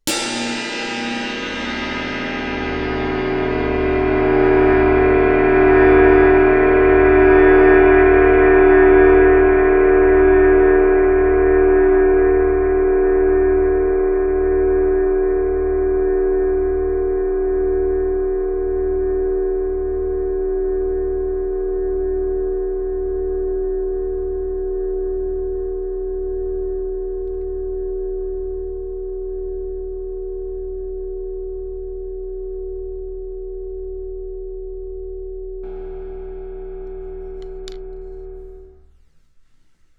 You then record a source and vary the mic position as the source sound decays, allowing you to pick up more of the detail in the decay of the sound.
I also experimented with moving the mic around the cymbal surface to capture different harmonics.
flying-cymbal-2.wav